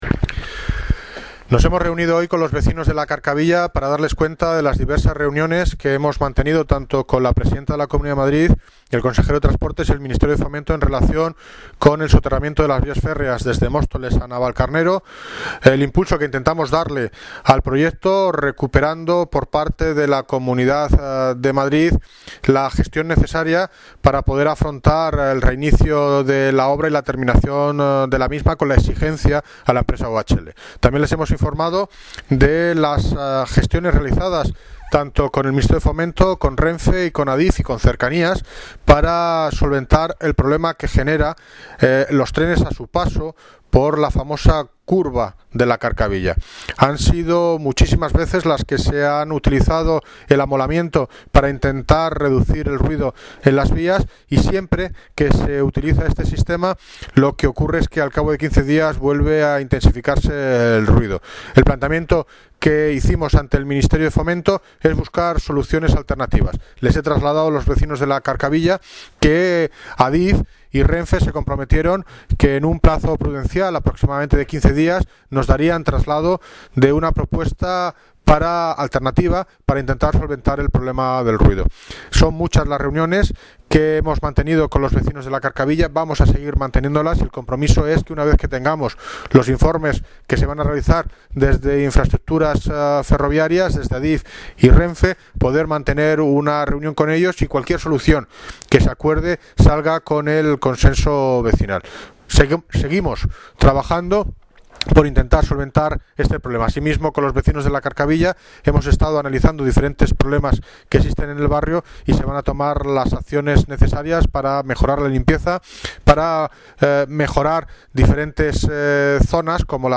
Audio - David Lucas (Alcalde de Móstoles) Sobre con vecinos Carcavilla